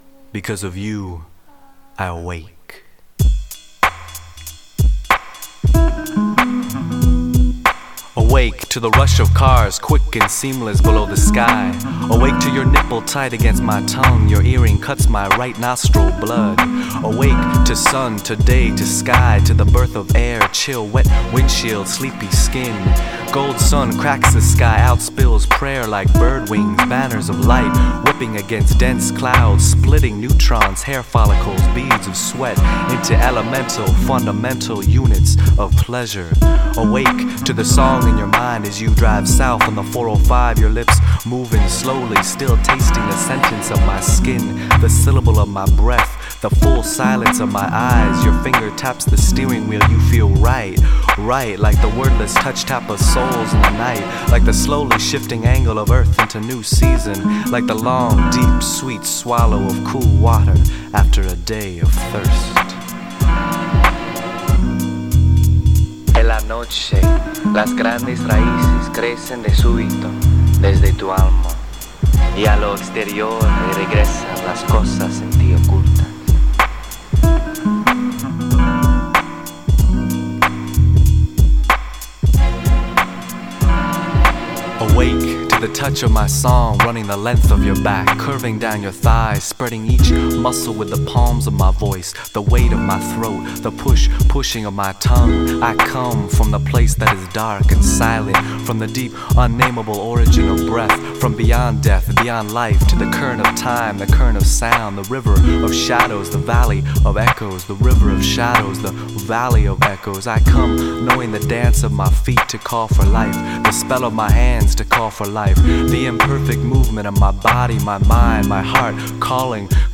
Filipina/o American Poetry and Spoken Word from Los Angeles